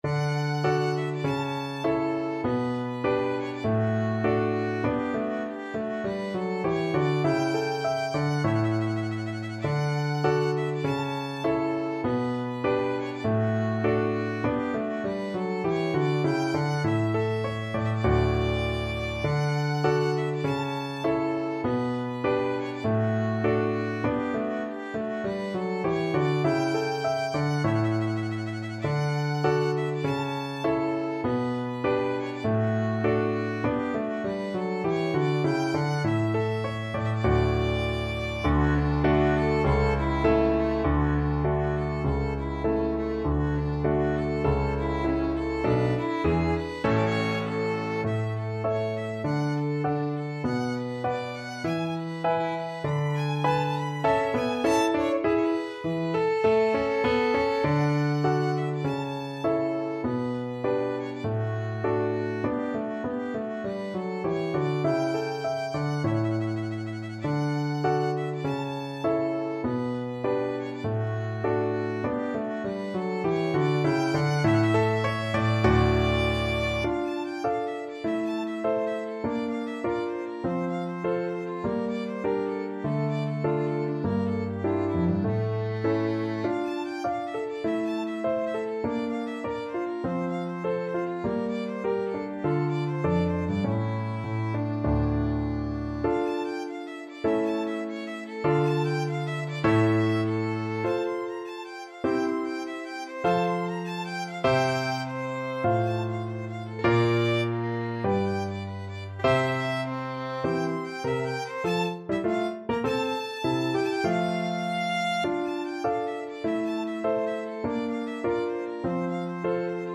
2/2 (View more 2/2 Music)
~ = 100 Allegretto